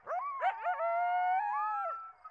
Койот (Canis latrans).